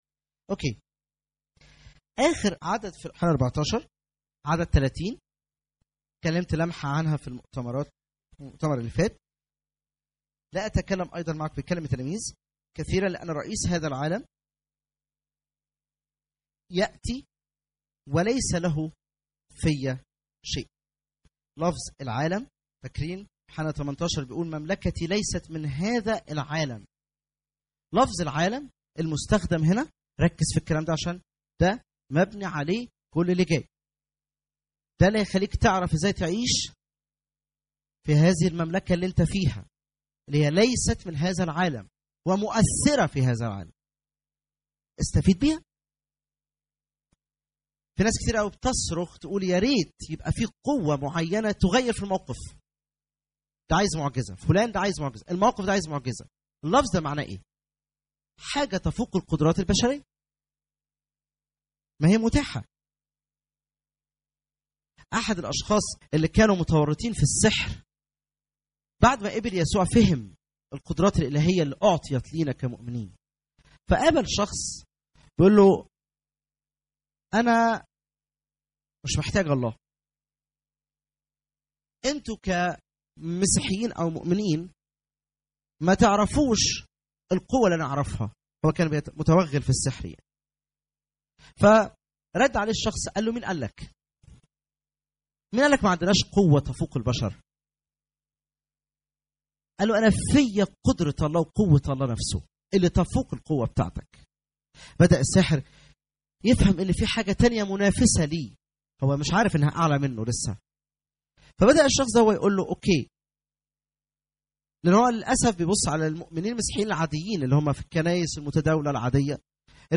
* مؤتمر مارس 2014 مملكة ليست من هذا العالم